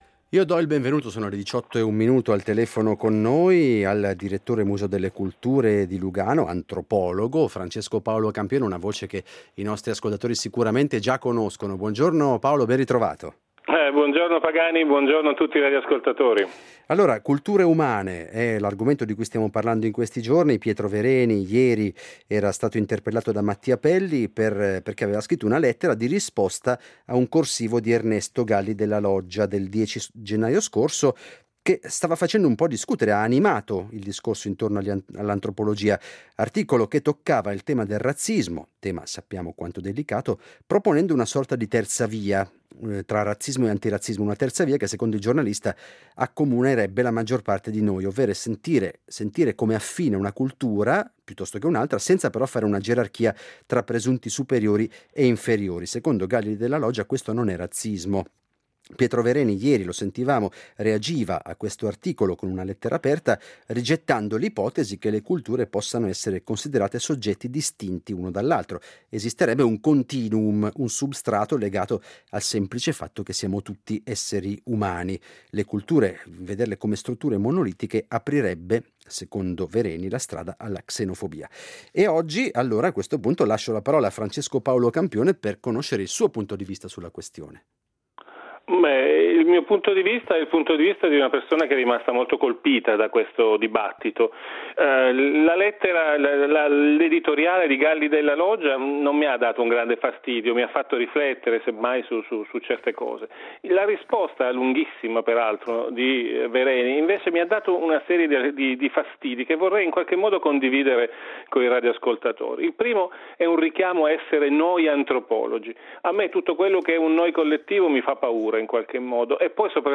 oggi ospitiamo in diretta